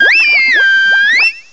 cry_not_primarina.aif